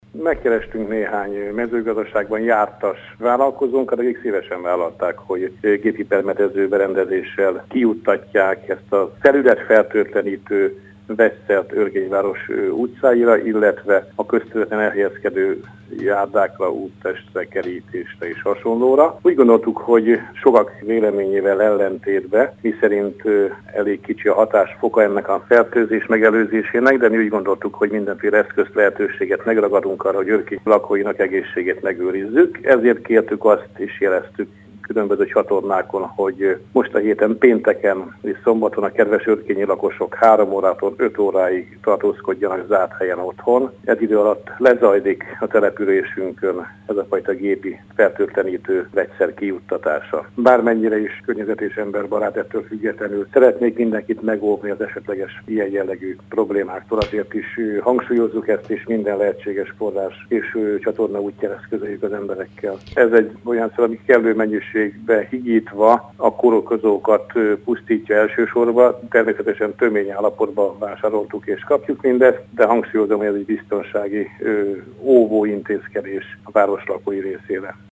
Kulcsár István polgármestert hallják: